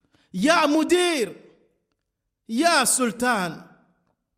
يامدير ياسلطان ya modir ya sultan phrase in Libyan تقال عندما يطلب العامل من مديره خبزه العامل : يامدير ياسلطان المدير : شنو شني تبي؟؟